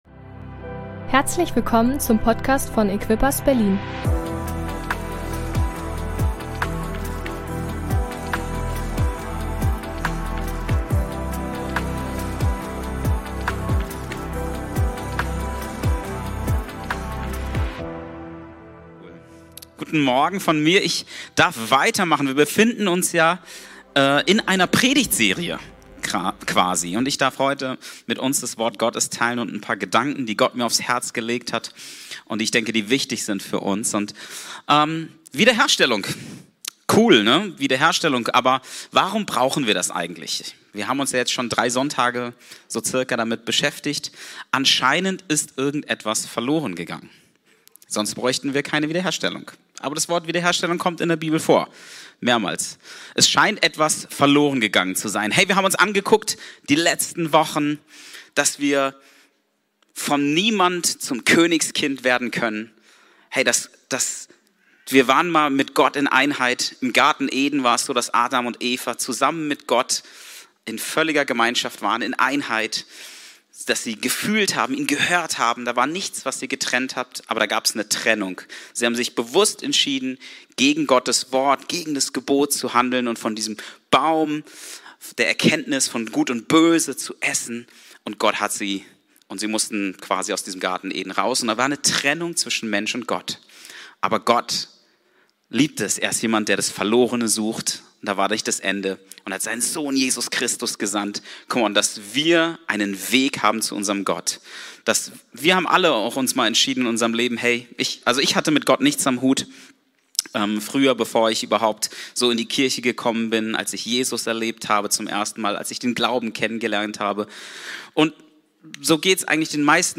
Predigt 4. Advent | Wiederherstellung